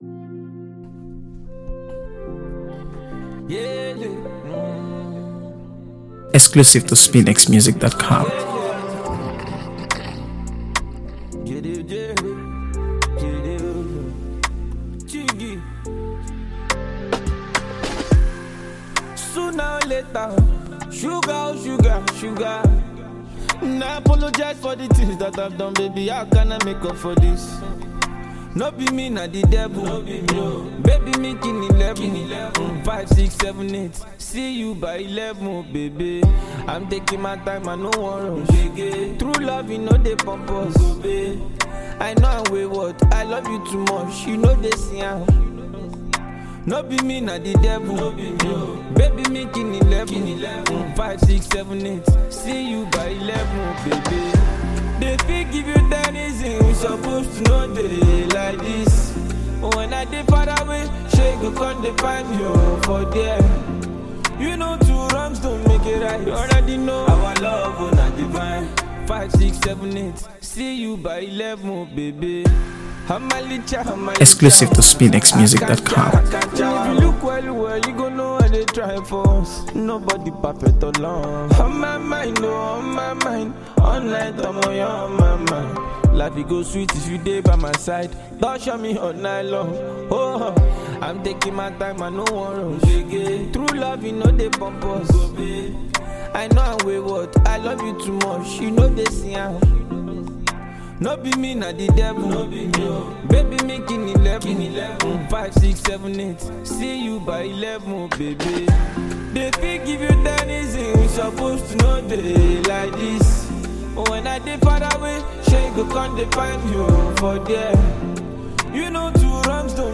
AfroBeats | AfroBeats songs
soulful music